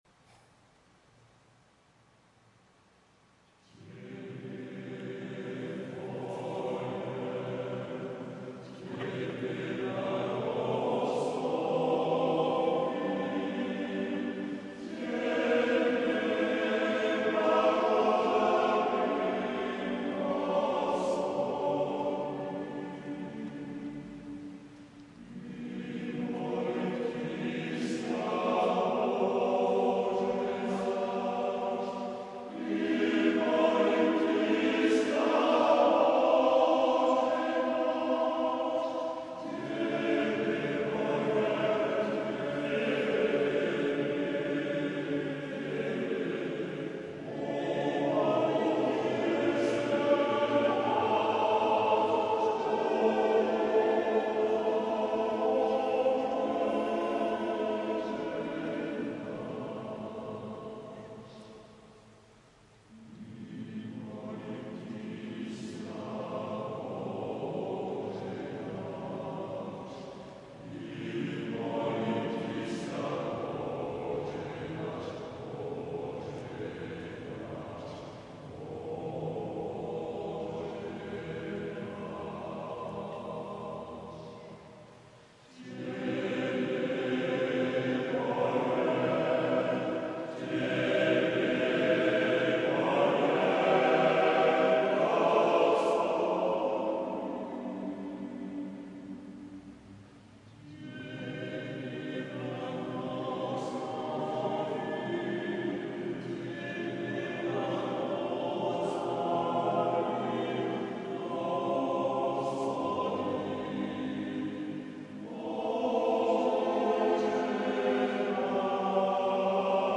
AATTBB (7 voces Coro de hombres) ; Partitura general.
Plegaria. Ortodoxa.